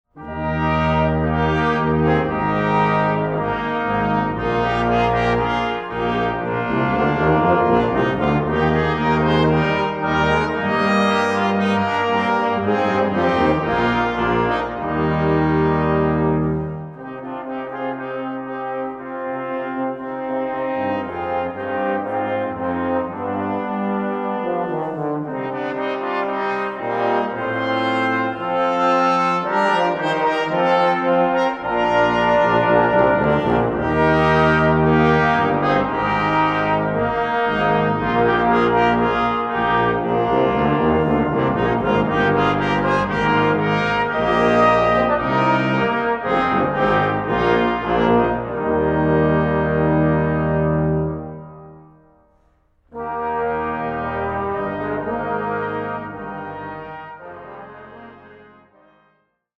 Aufnahme: Jesus-Christus-Kirche Berlin-Dahlem, 2011